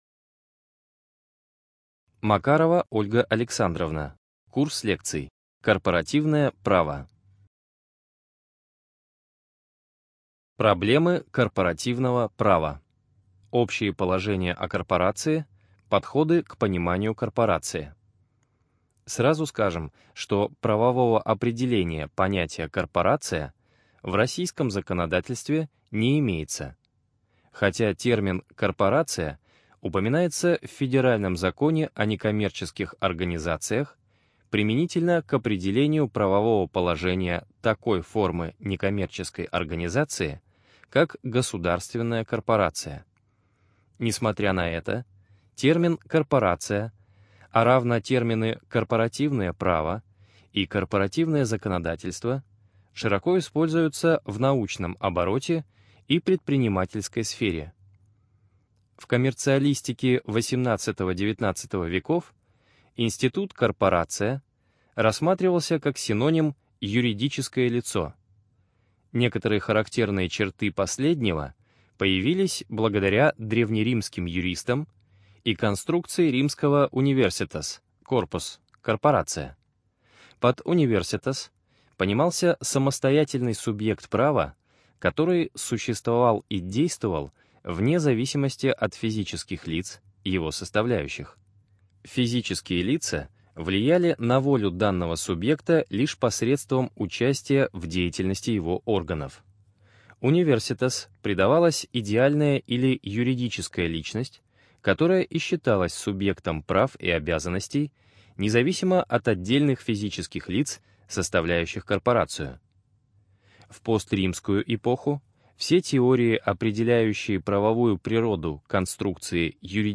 НазваниеКорпоративное право, курс лекций